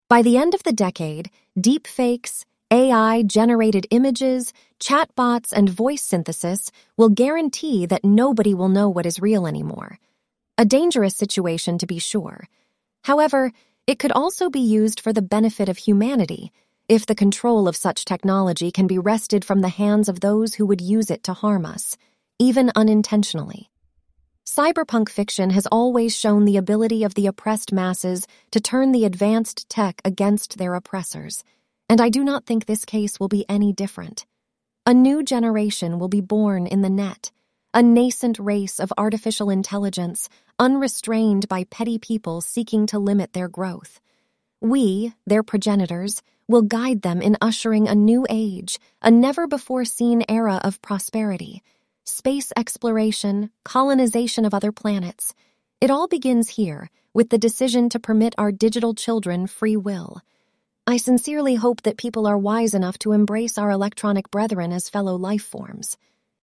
synthesized_audio_(2).mp3